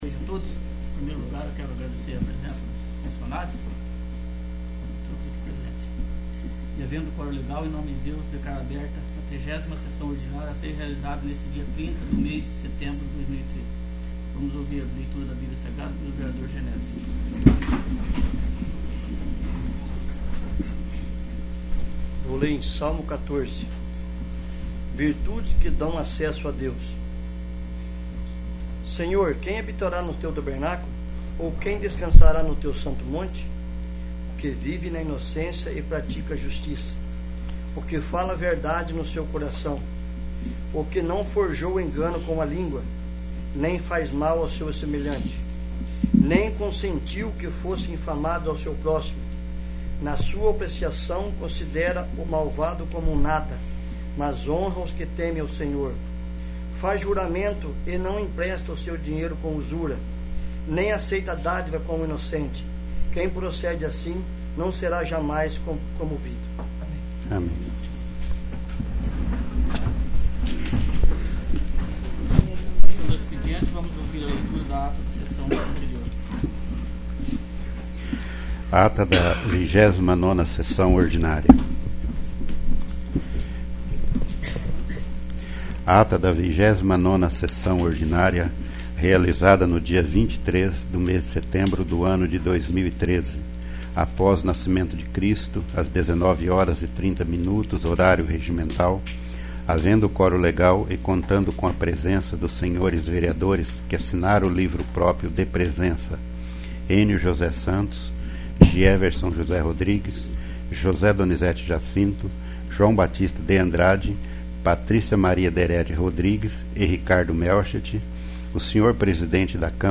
30º. Sessão Ordinária